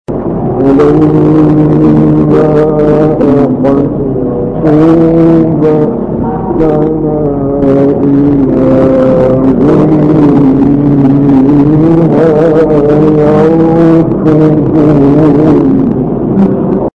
9 فراز از «کامل یوسف» در مقام صبا
به گزارش خبرگزاری بین‌‌المللی قرآن(ایکنا) 9 مقطع صوتی از کامل یوسف البهتیمی، قاری برجسته مصری که در مقام صبا اجرا شده، در کانال تلگرامی کامل یوسف البهتیمی منتشر شده است، در زیر ارائه می‌شود.
برچسب ها: خبرگزاری قرآن ، ایکنا ، شبکه اجتماعی ، کامل یوسف البهتیمی ، قاری مصری ، مقام صبا ، فراز صبا ، تلاوت قرآن ، مقطعی از تلاوت ، قرآن ، iqna